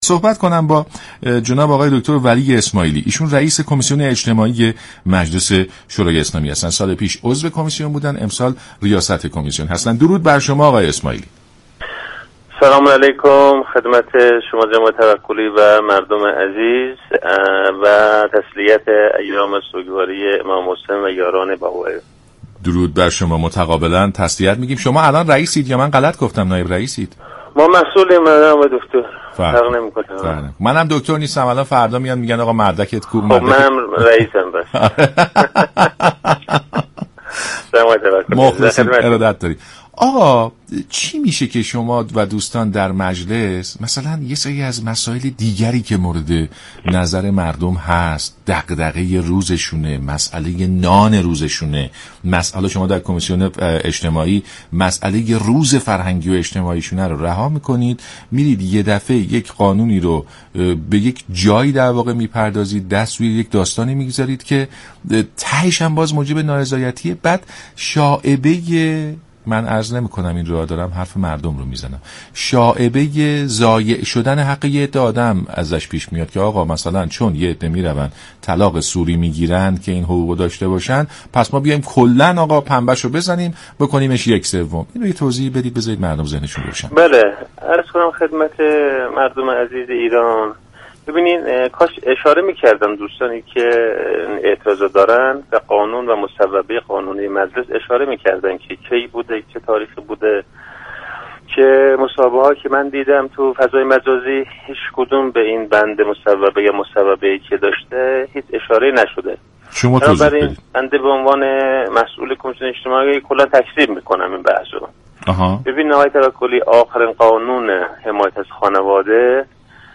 ولی اسماعیلی رییس كمیسیون اجتماعی مجلس گفت: مجلس در قانون حمایت از خانواده هیچگونه تغییری انجام نداده است
در برنامه سلام صبح بخیر رادیو ایران